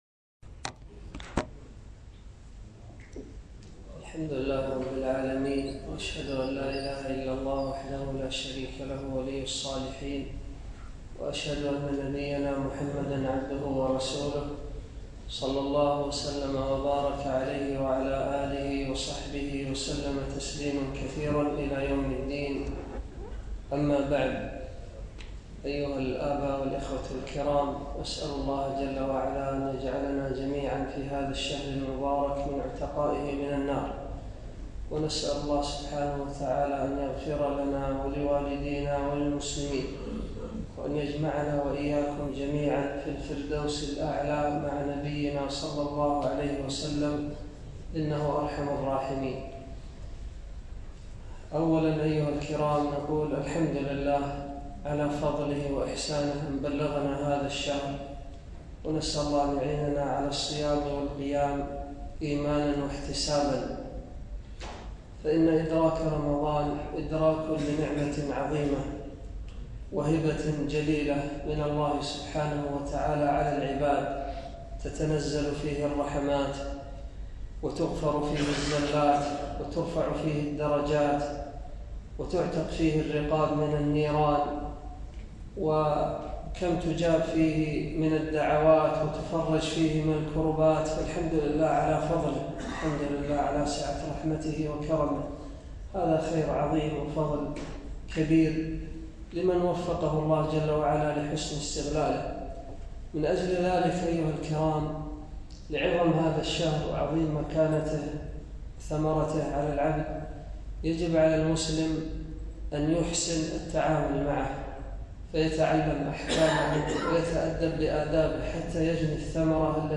ألقيت عصر يوم السبت ٦ رمضان ١٤٤٥ في مسجد فهد الطريجي بالزهراء